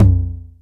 • Low Floor TR 909 Synth Tom Sample F Key 43.wav
Royality free tr 909 electronic tom sample tuned to the F note. Loudest frequency: 162Hz
low-floor-tr-909-synth-tom-sample-f-key-43-iAD.wav